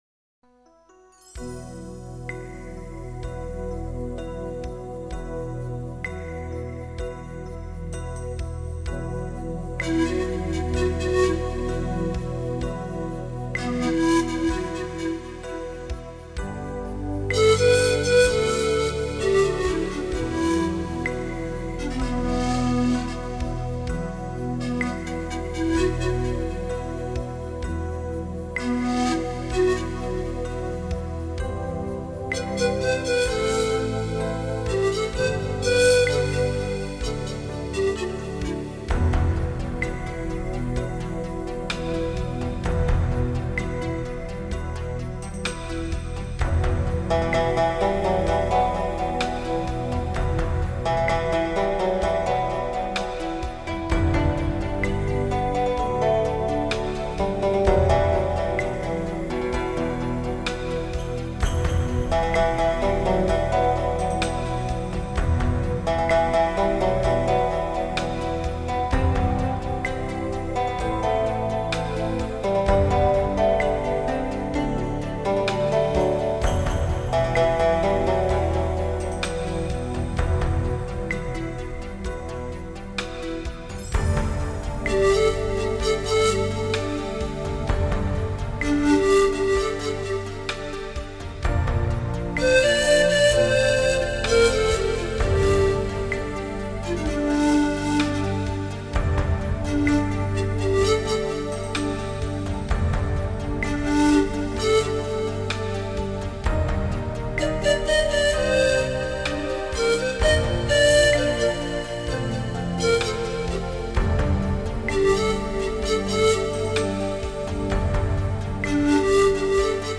New age Нью эйдж Музыка релакс Relax